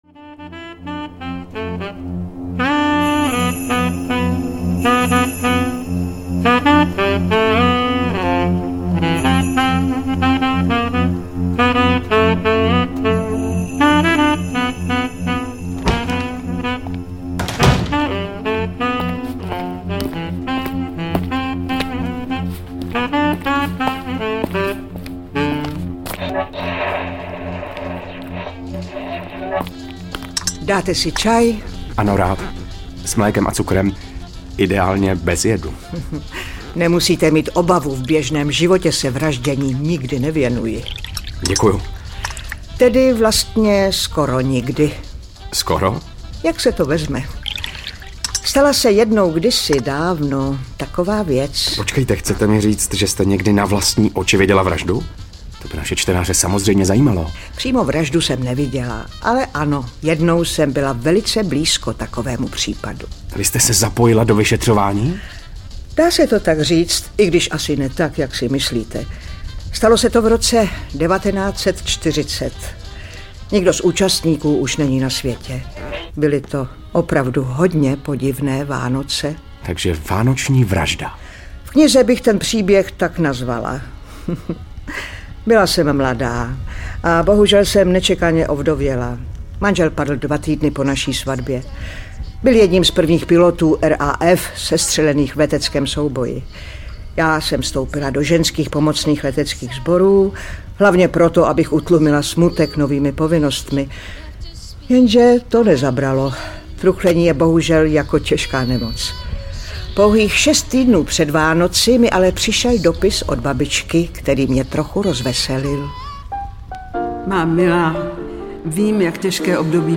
Vánoční vražda audiokniha
Ukázka z knihy